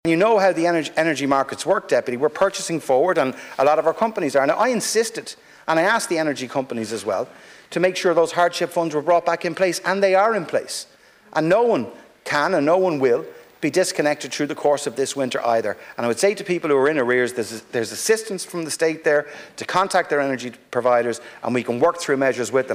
In the Dáil this afternoon, opposition parties criticised the Government for not doing enough to help people struggling to pay their energy bills.
Climate and Energy Minister Darragh O'Brien defended his position.